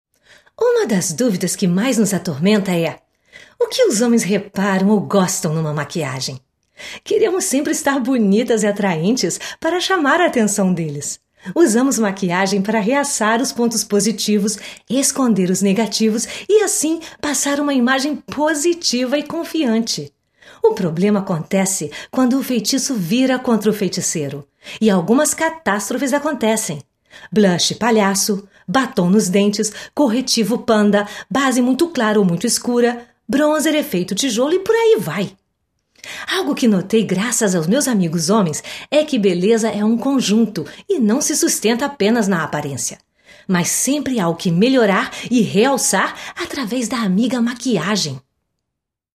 Portugiesich/Brasilianische Sprachaufnahmen von professioneller brasilianischer Schauspielenrin für Image, VO, Werbung und Ansagen.
Sprechprobe: Sonstiges (Muttersprache):